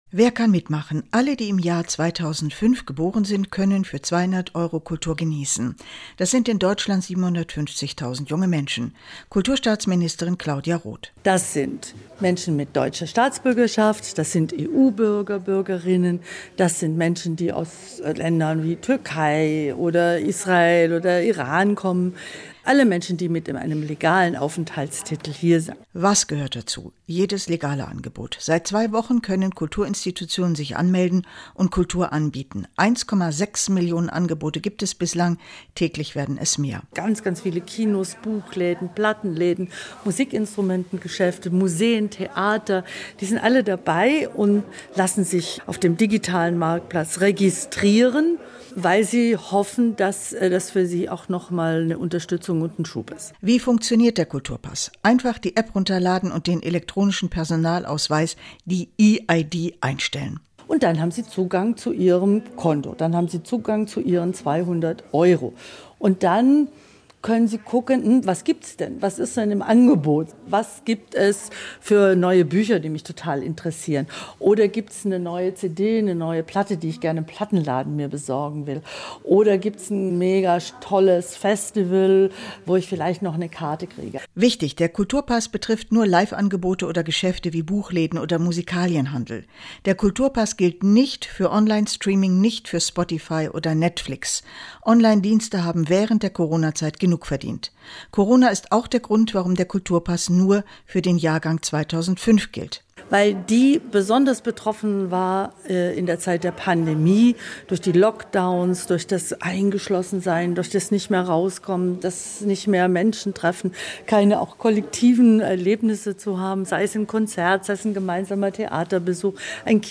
Inforadio Nachrichten, 29.07.2023, 19:00 Uhr - 29.07.2023